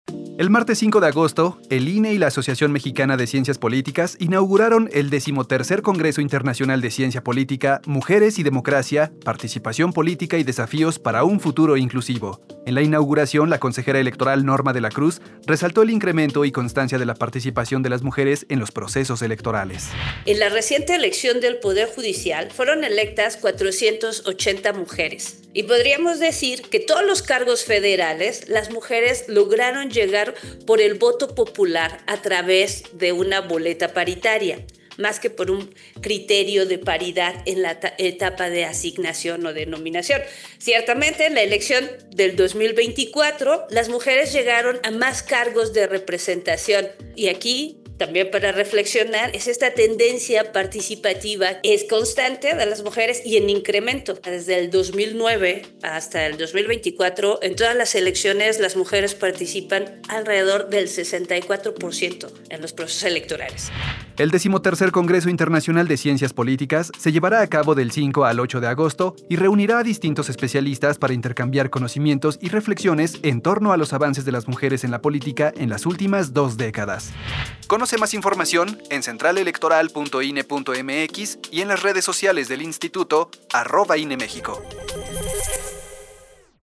Participación de la Consejera Norma De La Cruz en la inauguración del XIII Congreso Internacional de Ciencia Política de la AMECIP